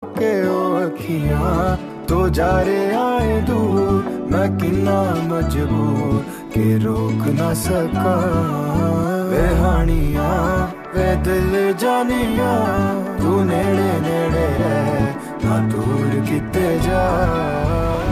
heartfelt melody
Categories Punjabi Ringtones